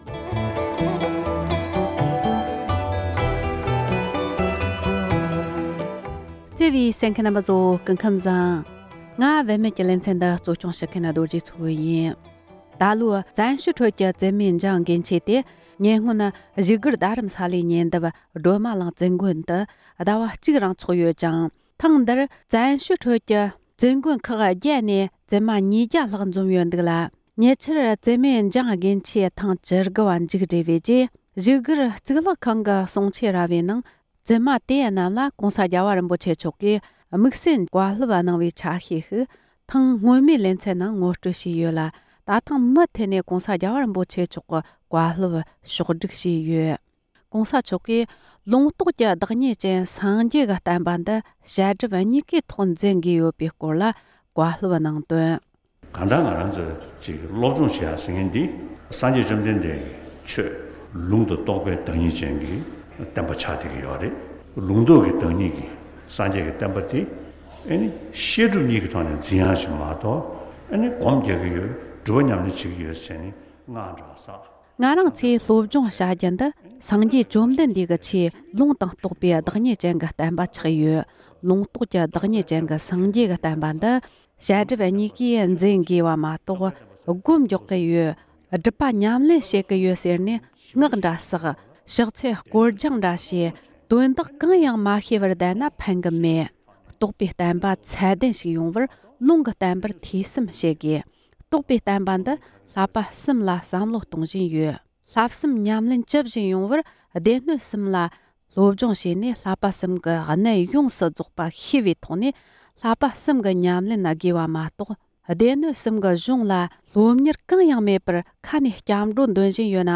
༸གོང་ས་མཆོག་ནས་འདི་ལོའི་བཙུན་མའི་འཇང་དགུན་ཆོས་སྐབས་བསྩལ་བའི་བཀའ་སློབ།